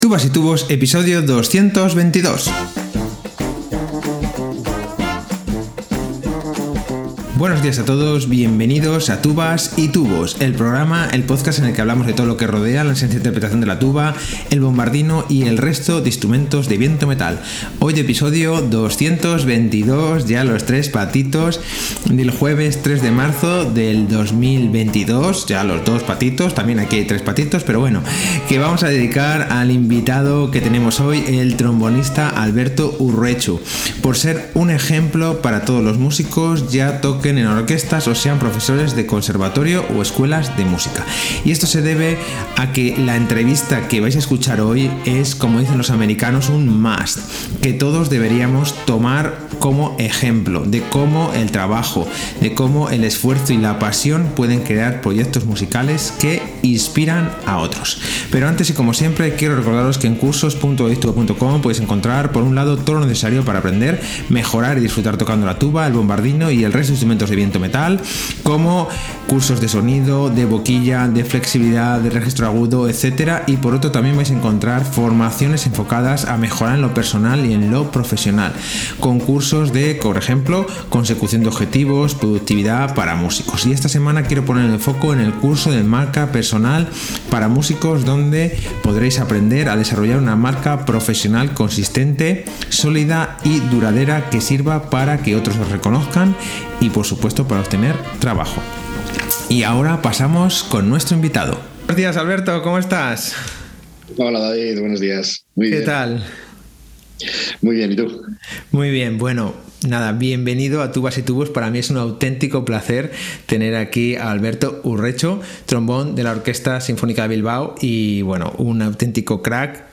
Interesante entrevista